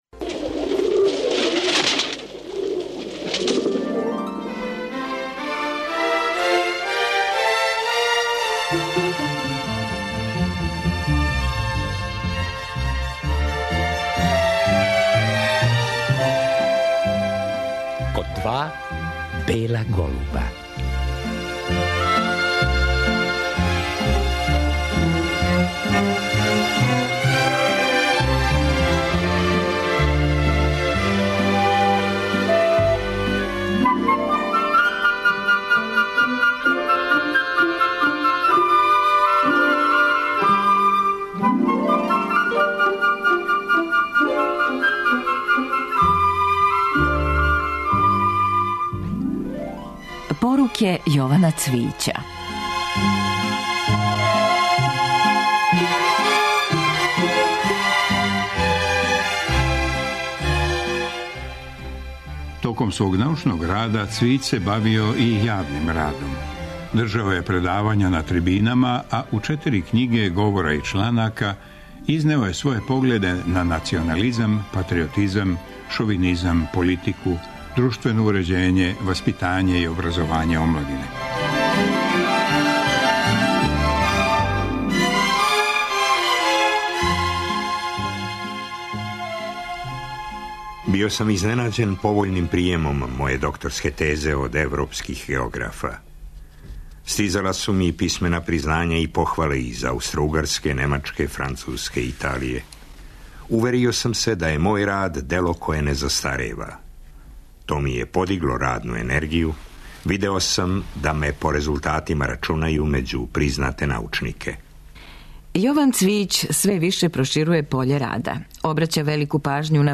Део тог разговора поново емитујемо.